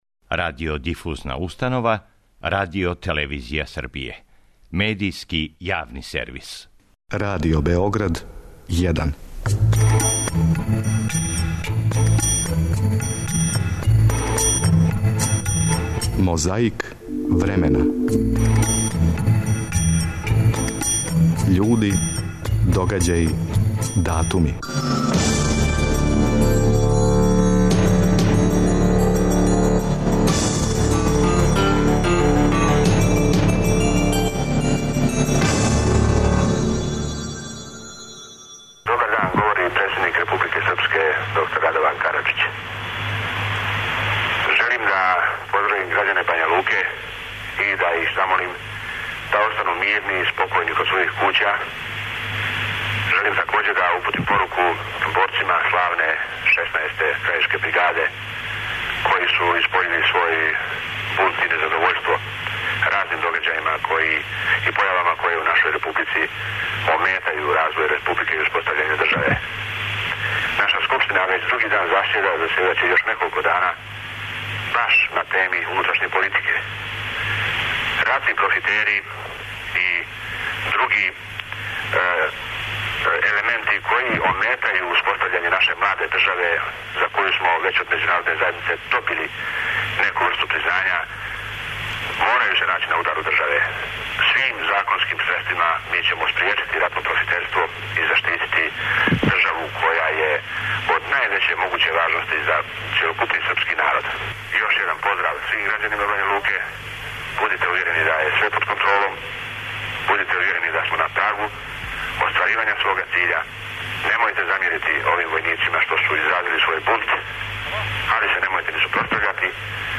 Звучна коцкица нас враћа на 10. септембар 1993. године када је Радован Караџић започео своје обраћање грађанима Бања Луке после побуне 16. Крајишке бригаде.
Подсећа на прошлост (културну, историјску, политичку, спортску и сваку другу) уз помоћ материјала из Тонског архива, Документације и библиотеке Радио Београда. Свака коцкица Мозаика је један датум из прошлости.